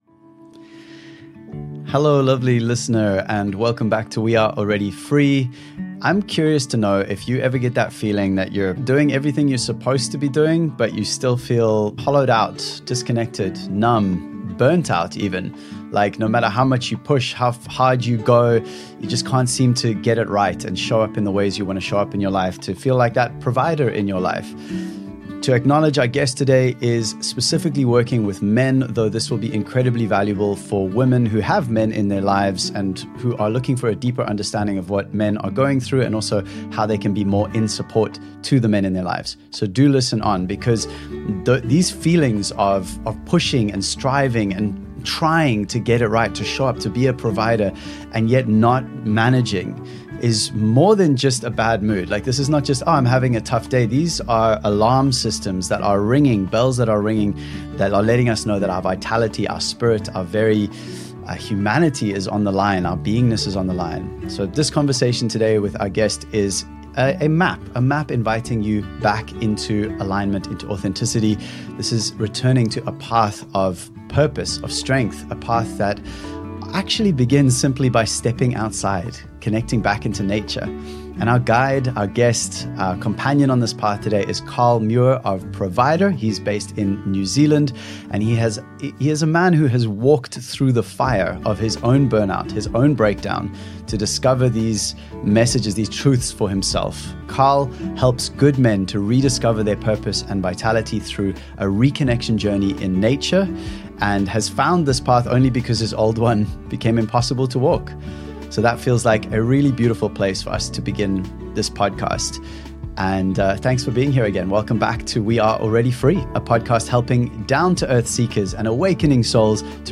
This conversation on the We Are Already Free podcast is a map back to alignment, for both men and the women who love them.